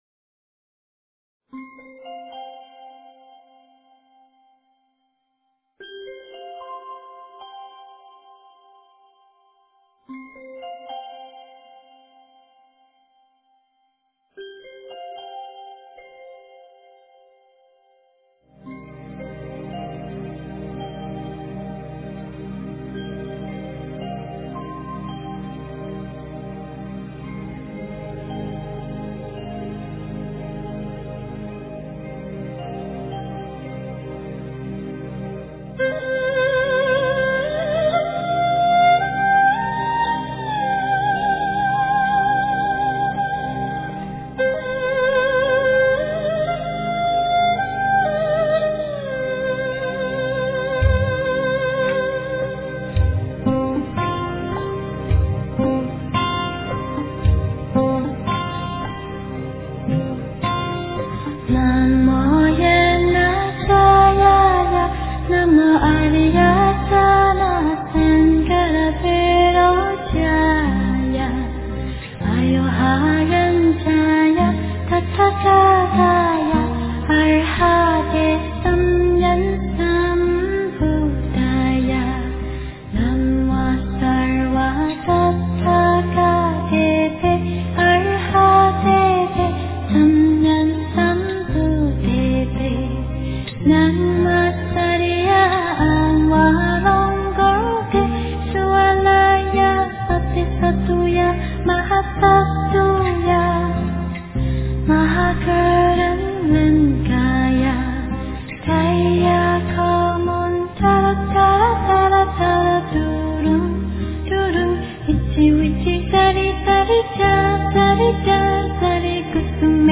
大悲咒 诵经 大悲咒--童音 点我： 标签: 佛音 诵经 佛教音乐 返回列表 上一篇： 大悲咒心咒 下一篇： 千手千眼无碍大悲心陀罗尼-大悲咒 相关文章 在水中央-古筝--未知 在水中央-古筝--未知...